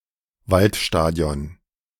Waldstadion (German pronunciation: [ˈvaltˌʃtaːdi̯ɔn] ⓘ; German: Forest Stadium) is the name of several stadia or football grounds in Germany and Austria:
De-Waldstadion.ogg.mp3